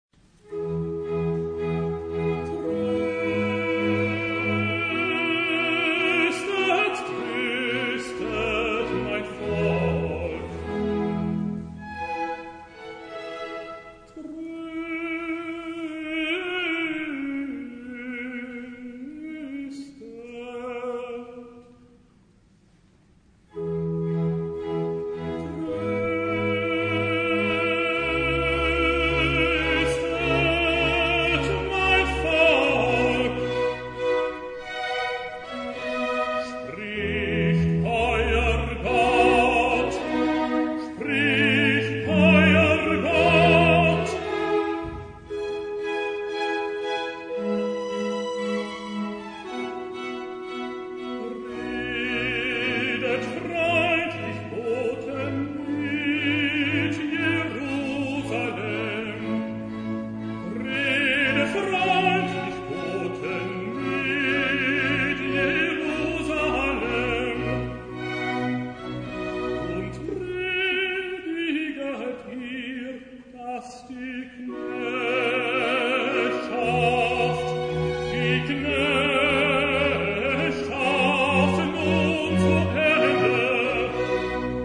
Hörbeispiel: Messias Arie Tröstet mein Volk